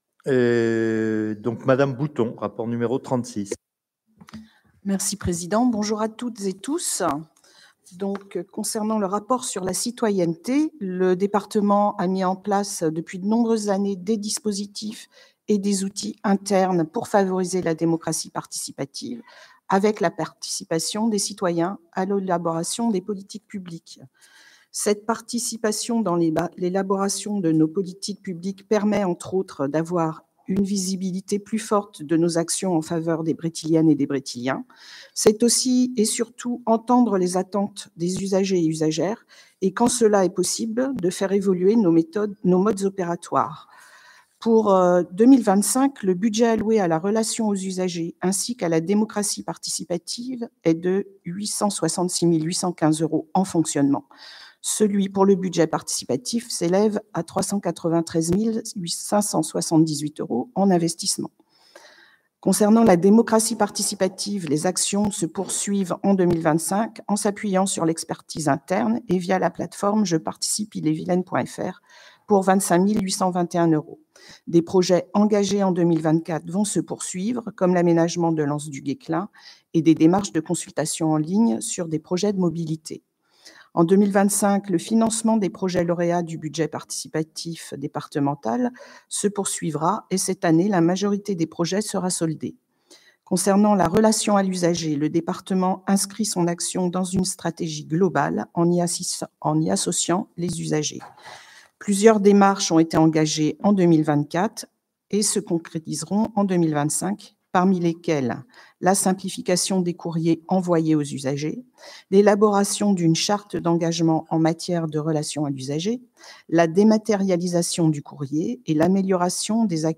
• Assemblée départementale du 19/03/25